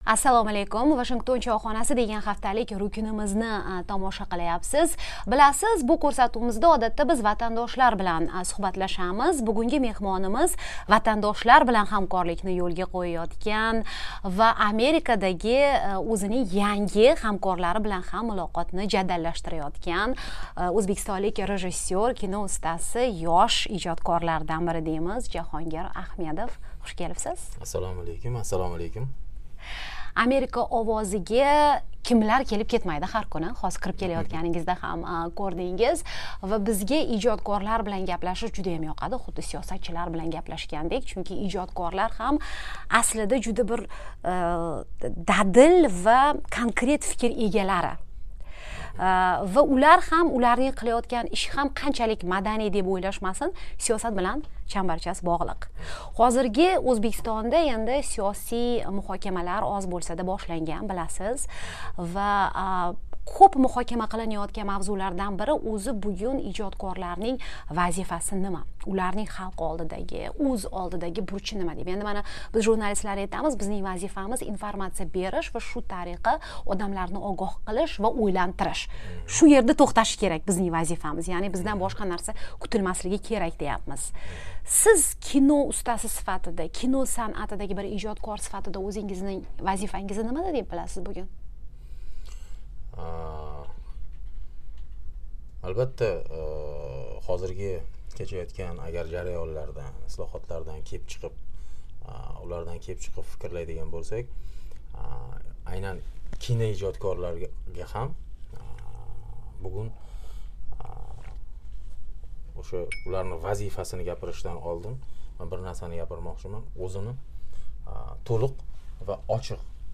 Mamlakatda ijodiy erkinlik qay darajada? Kino sanoati qanchalik shakllangan? Vashingtonda keng qamrovli suhbat.